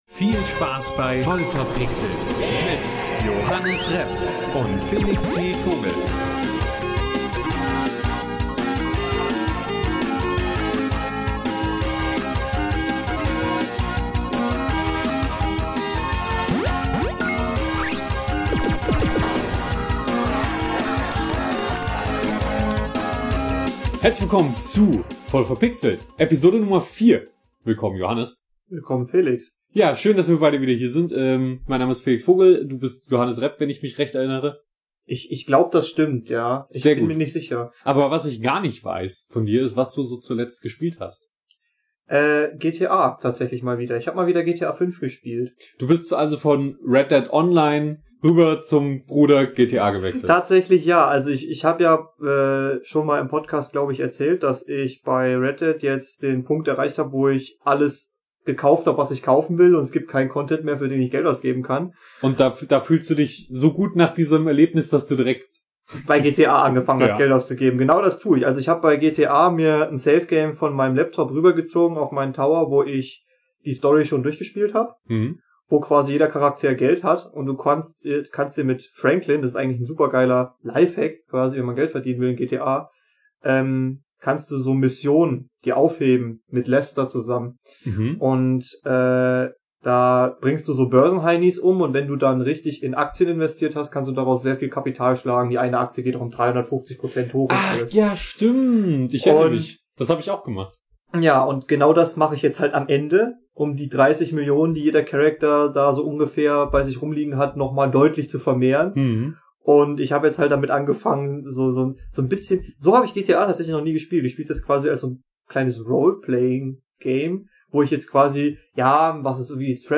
Anregende Gespräche, spaßige Geschichten und interessante Einblicke in die vielseitige Welt der Videospiele.